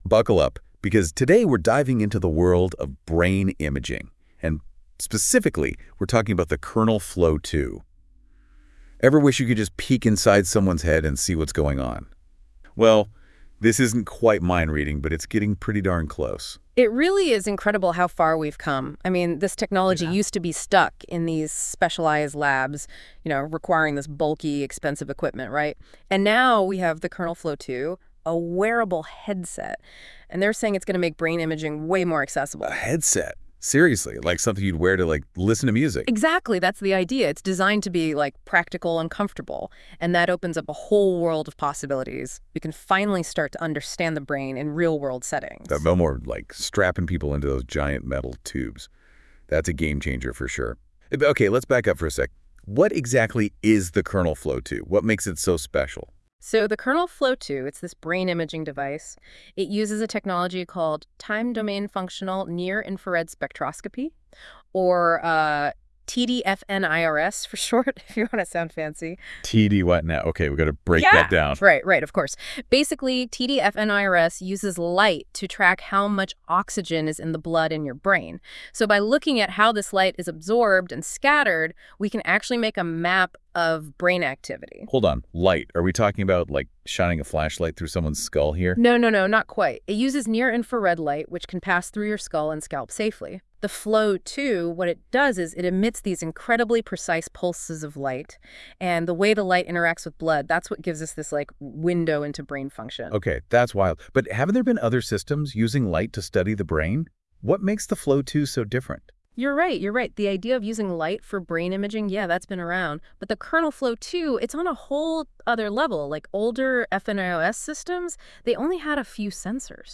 An AI generated podcast from NotebookLM that provides an accessible overview of our recent scientific results: Kernel Flow2